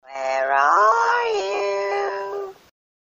Звуки бабки Granny
Вы можете скачать её леденящий душу смех, скрип дверей, удары молотка и другие жуткие звуковые эффекты в высоком качестве.